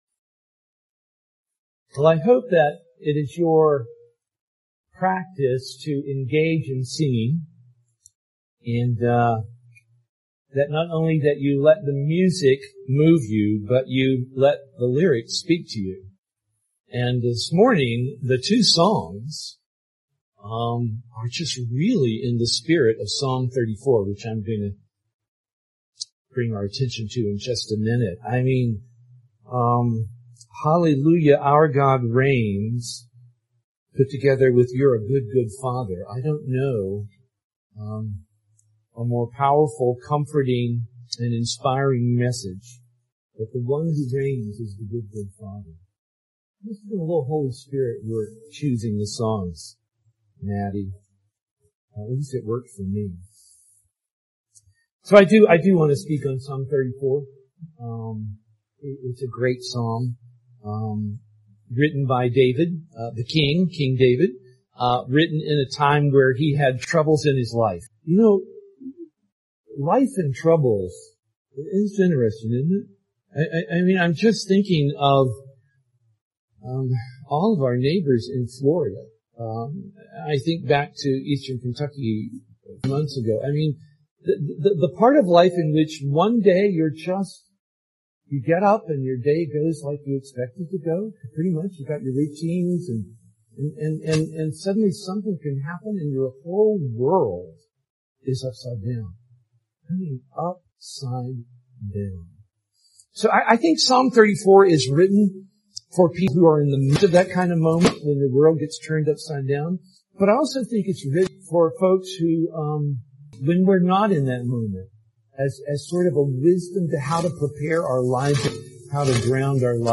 Posted on Oct 4, 2022 in Sermons, Worship |